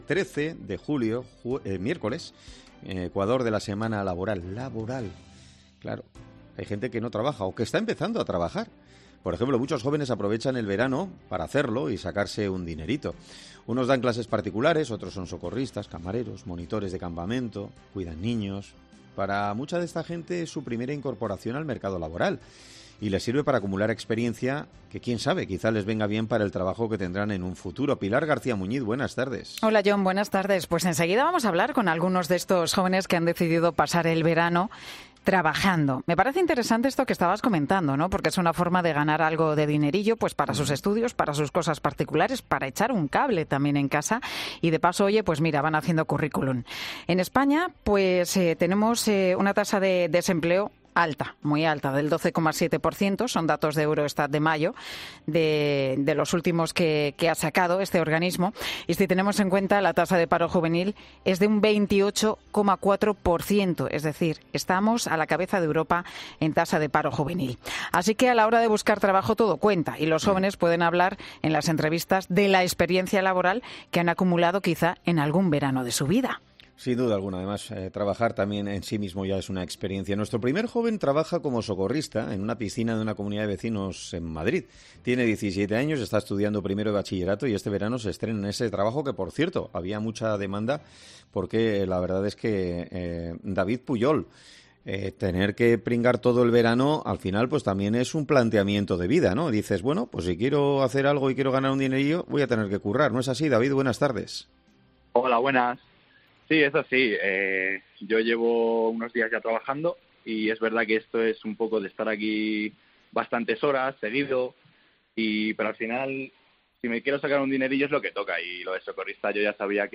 En 'Mediodía COPE' hablamos con distintos jóvenes que acaban de entrar en el mercado laboral